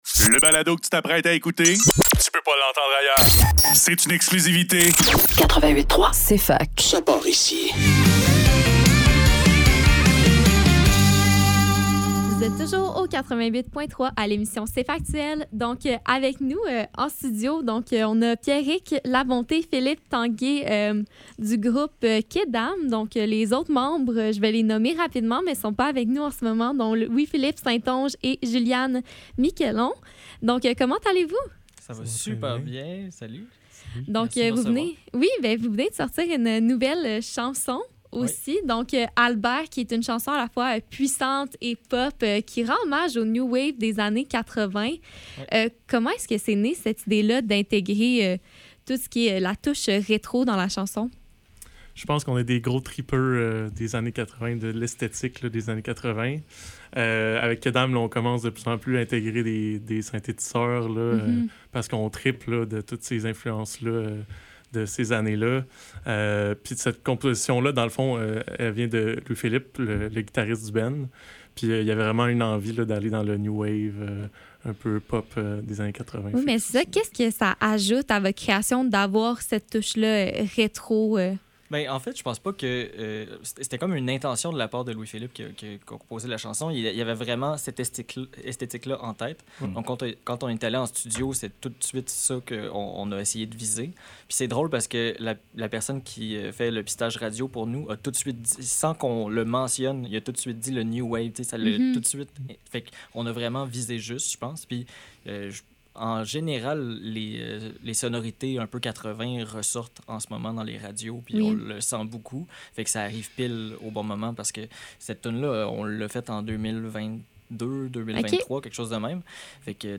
Cfaktuel - Entrevue
(en Studio)